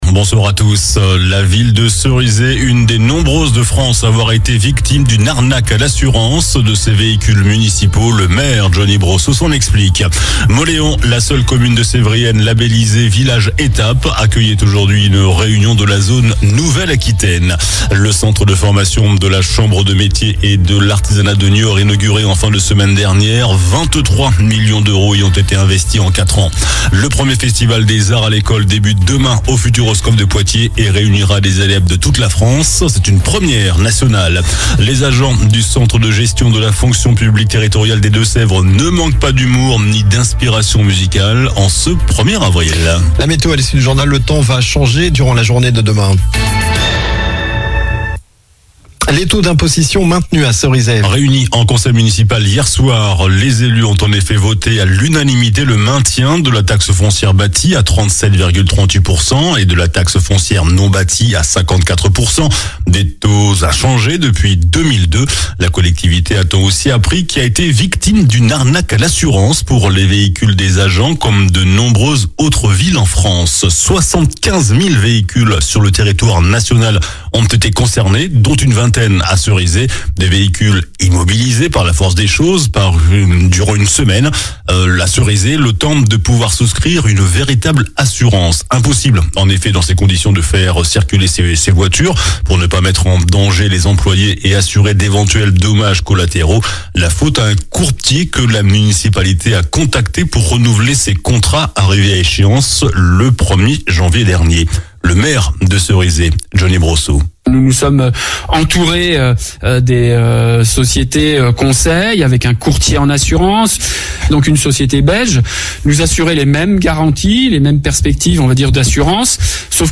JOURNAL DU MARDI 01 AVRIL ( SOIR )